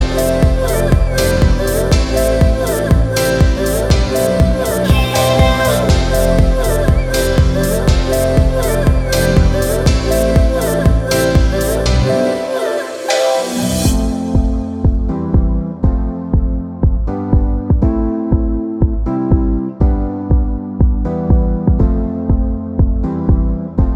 For Solo Female Pop (2010s) 4:45 Buy £1.50